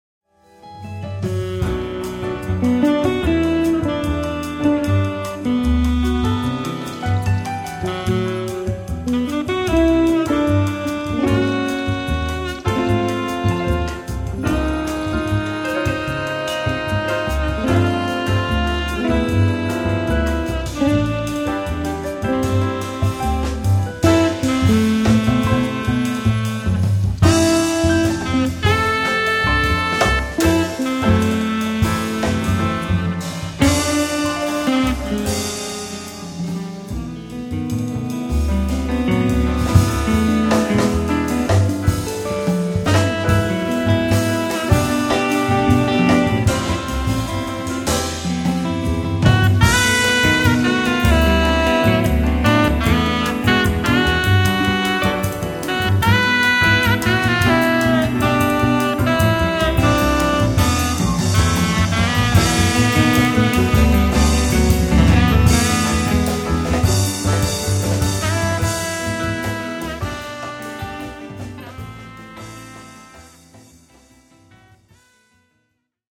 keyboards
acoustic bass
drums and percussion
saxophone